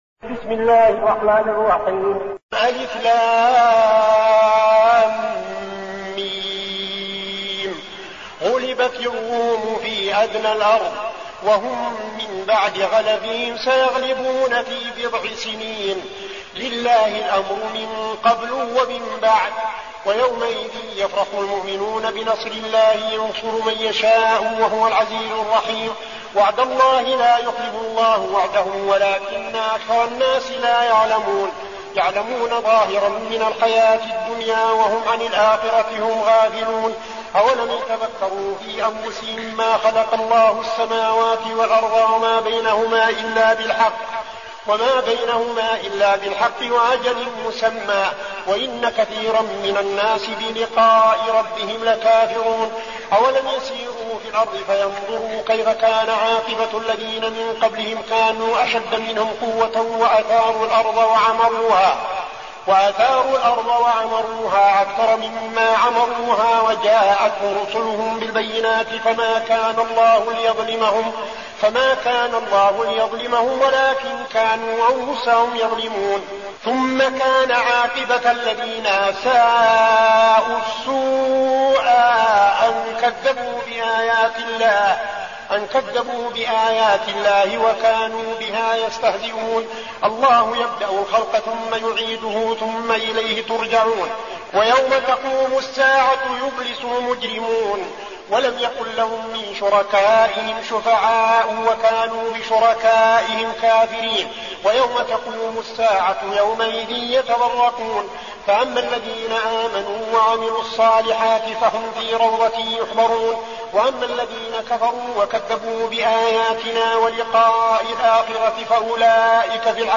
المكان: المسجد النبوي الشيخ: فضيلة الشيخ عبدالعزيز بن صالح فضيلة الشيخ عبدالعزيز بن صالح الروم